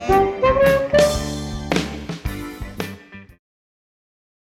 Что такое тёрнараунд – это пассаж в конце квадрата, который логически подводит нас к следующему квадрату.
Например, вот такой простой тернараунд: -2 +4 -4′ -4.